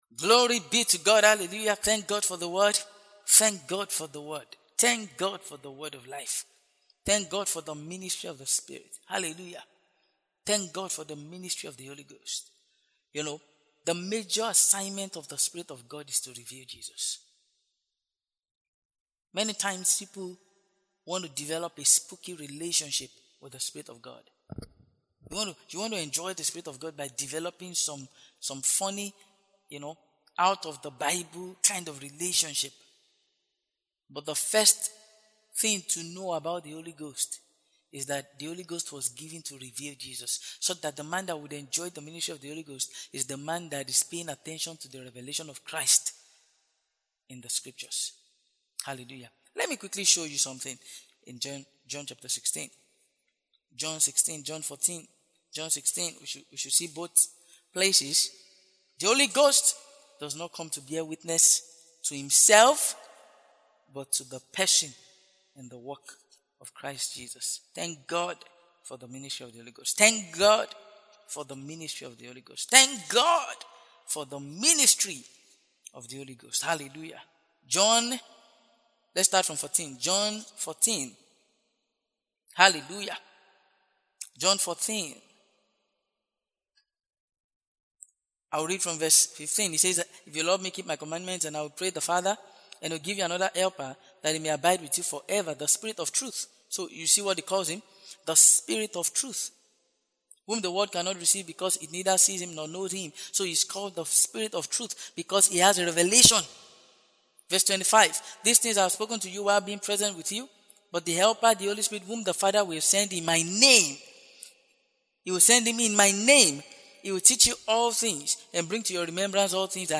This is the foundation laying series for the Everyday with Jesus Broadcast.